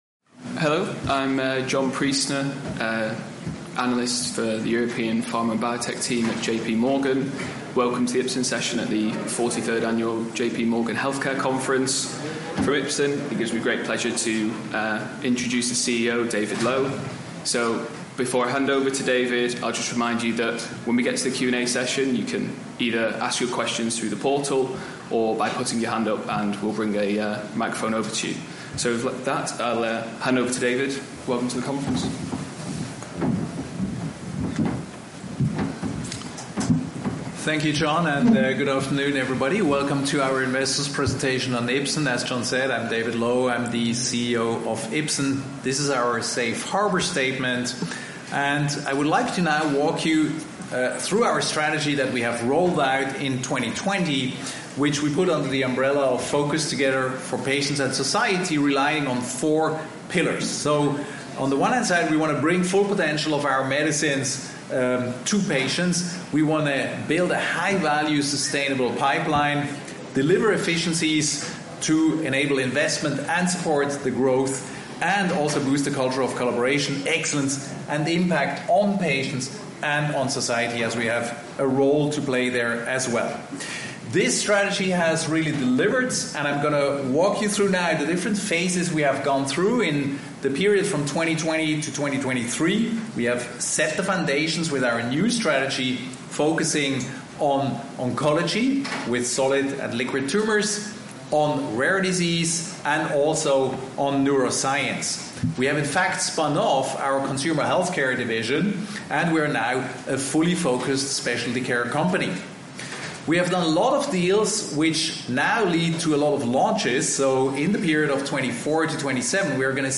J.P. Morgan Healthcare Conference - Global